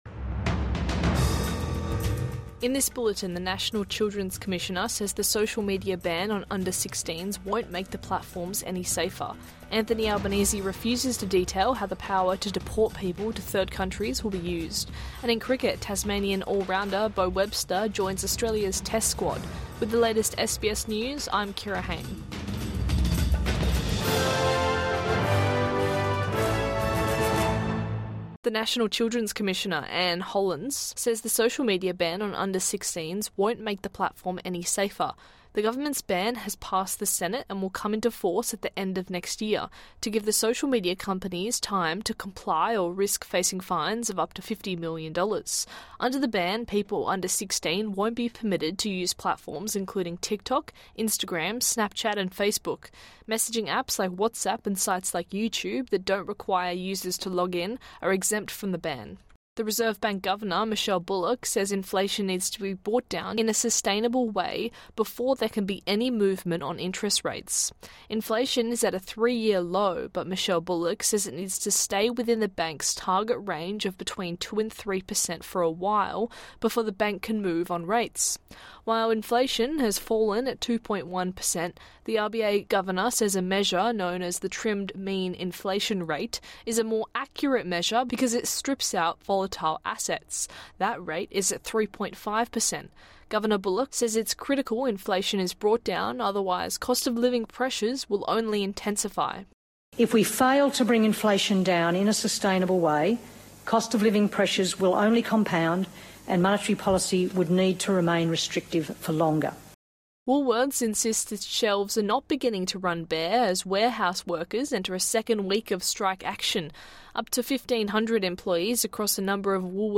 Midday News Bulletin 29 November 2024